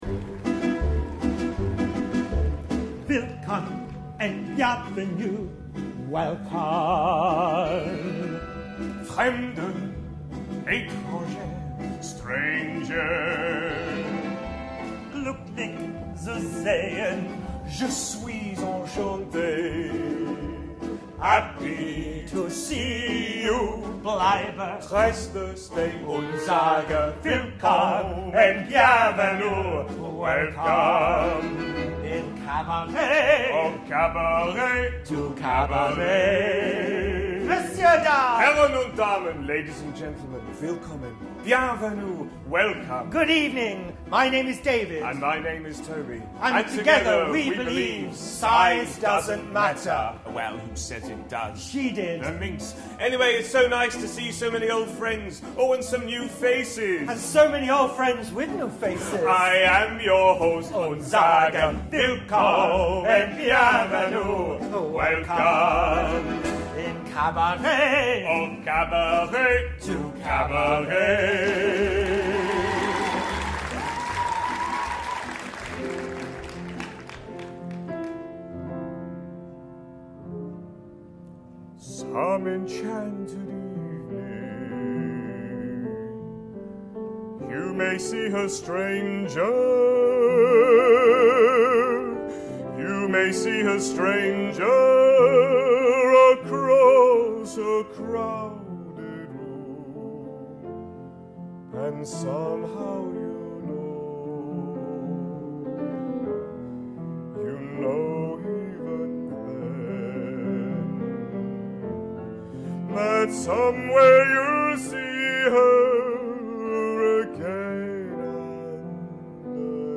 Classical Vocal Stage Show & piano
Take one Tenor, One Baritone, and One Piano - inject humour, classical music, music from the shows and an onstage chemistry in performance and you have The Opera Twosome.
Opera, Classical Music, Songs from the Shows, Classical Crossover
The-Opera-Twosome-Opera.mp3